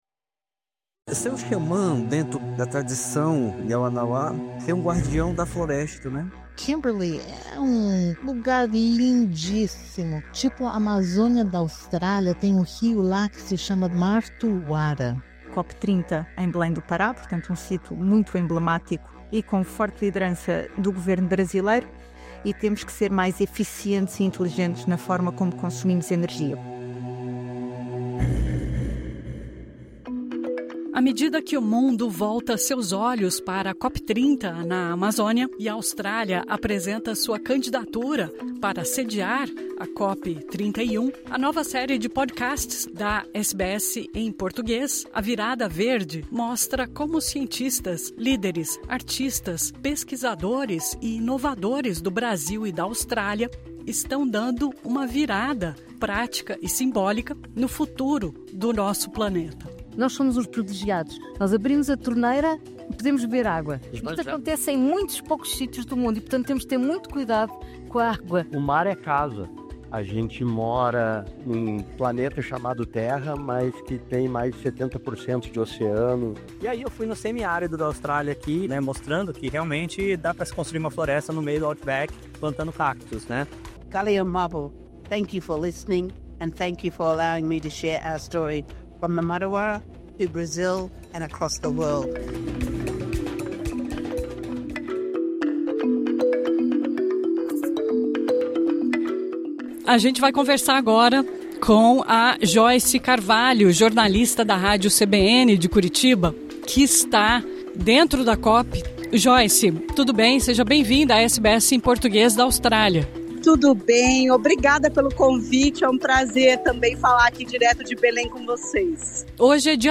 SBS em Português